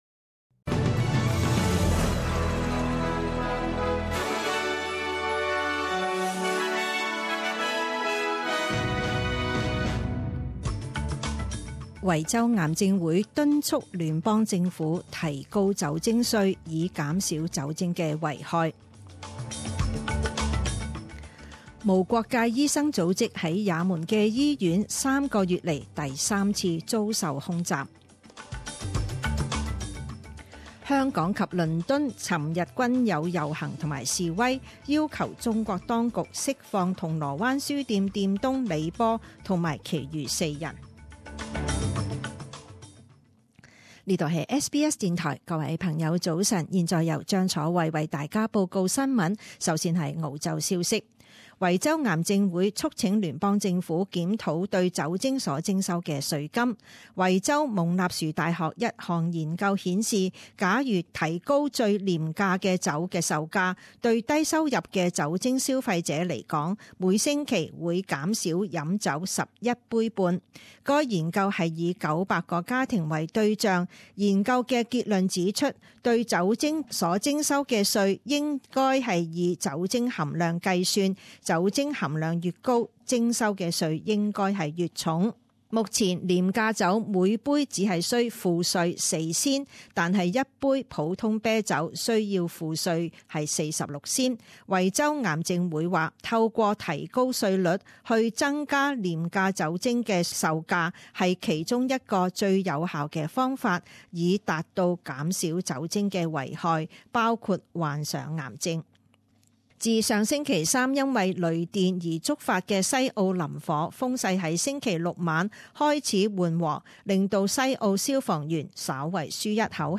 一月十一日十点钟新闻报导